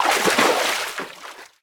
misscatch.ogg